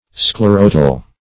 Search Result for " sclerotal" : The Collaborative International Dictionary of English v.0.48: Sclerotal \Scle*ro"tal\, a. (Anat.)